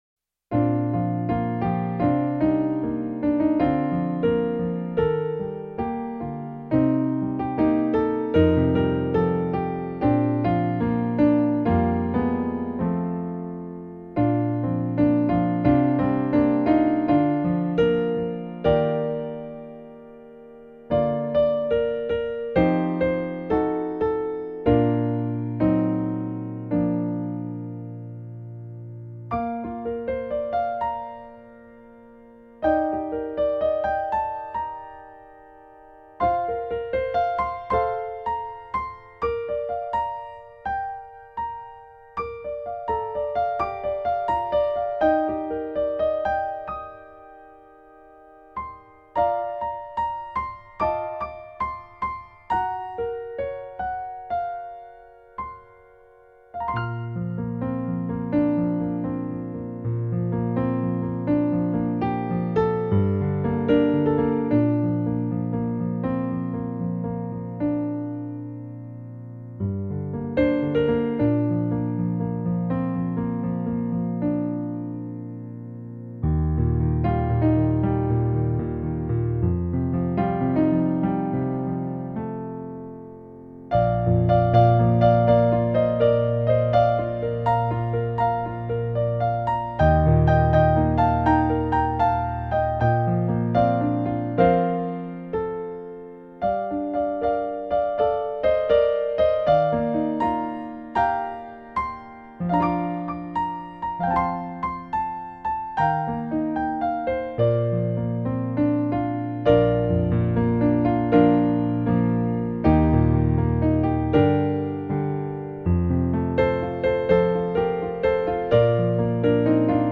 instrumentation: Piano Solo level